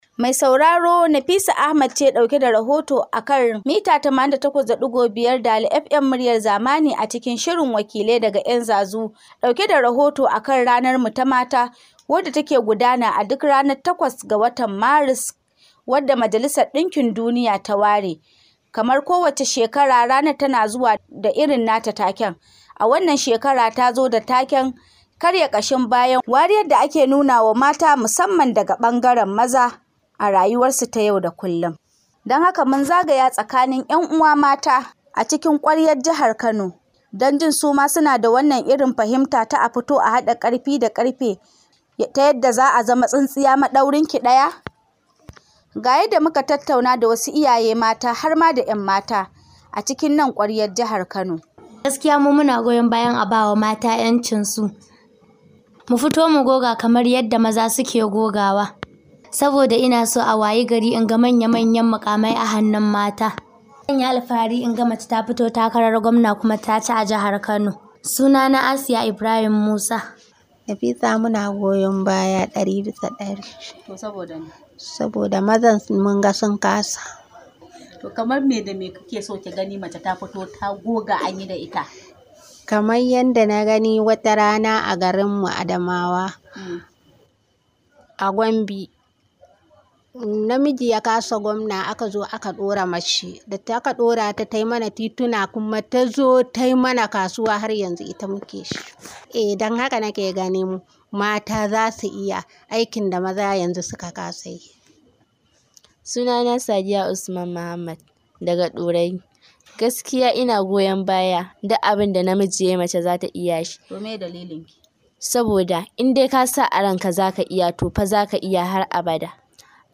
Wakiliyar tamu na da cikakken rahoton a Muryar da ke ƙasa.